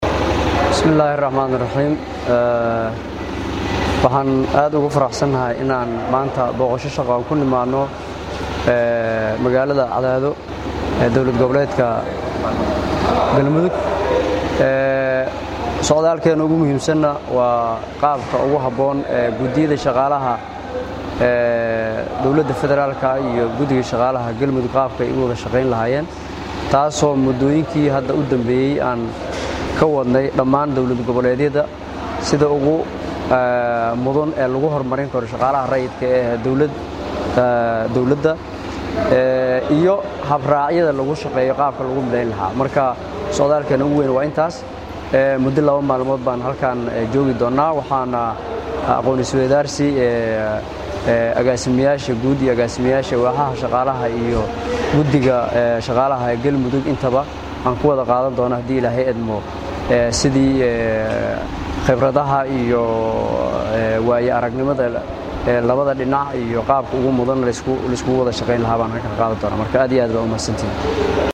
Gudoomiye Xasan Abshiroow Maxamad ayaa Warbaahinta qaranka uga waramay ujeedaada booqashadisa uu ku tagay magaalada cadaado.
Halkaan ka Dhageyso Codka Gudoomiyaha Shaqaalah Rayidka ah